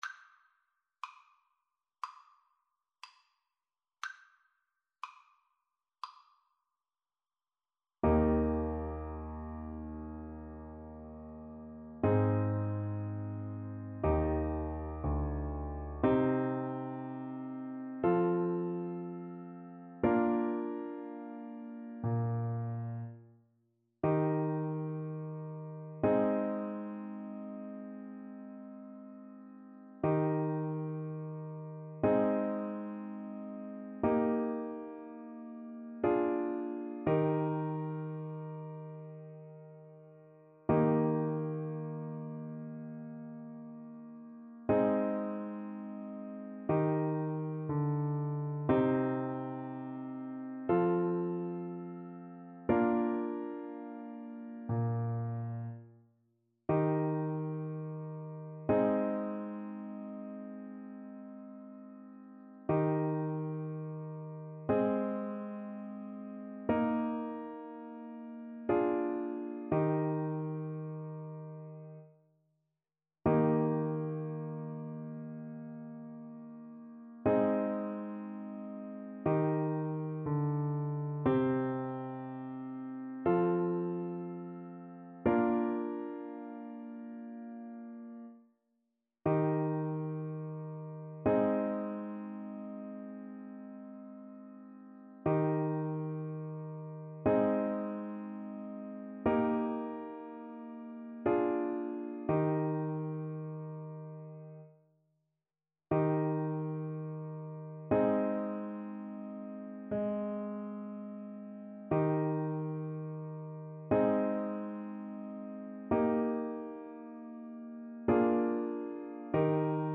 ~ = 100 Adagio
4/4 (View more 4/4 Music)
Classical (View more Classical Clarinet Music)